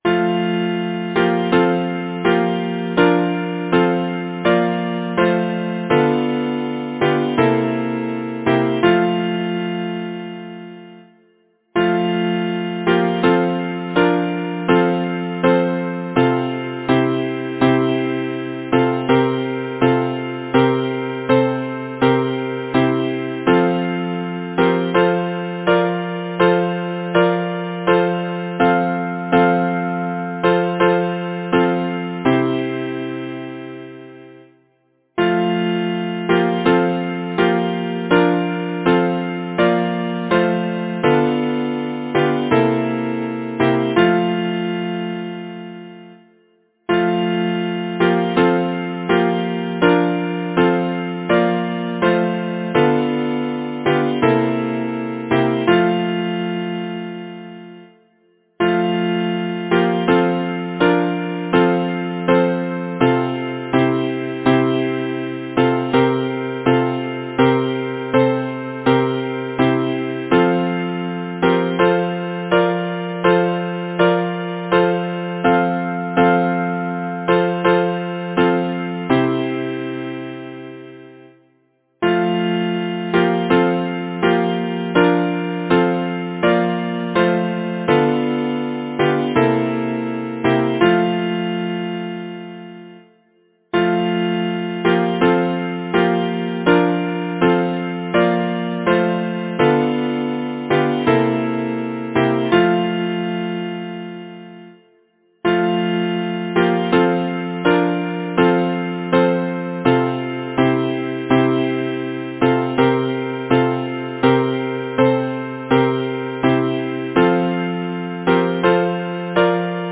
Title: Summer longings Composer: Howard Kingsbury Lyricist: Denis Florence MacCarthy Number of voices: 4vv Voicing: SATB Genre: Secular, Partsong
Language: English Instruments: A cappella